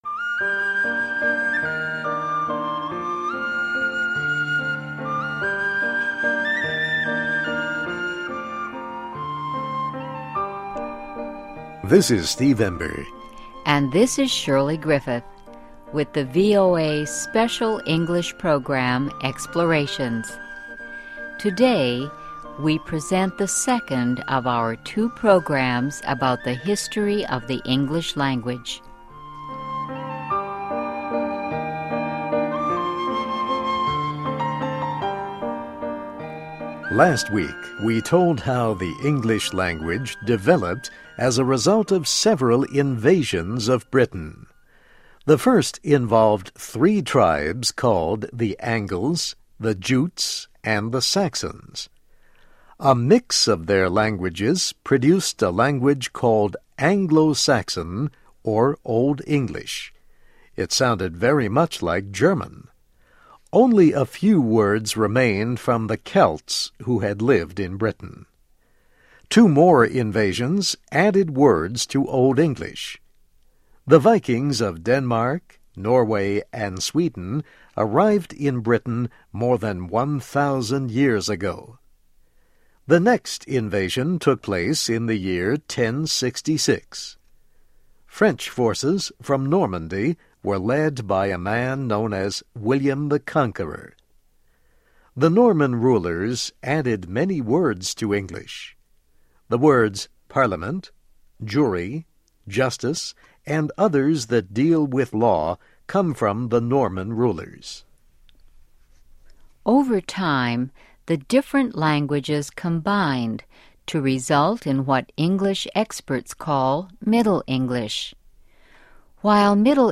(MOVIE)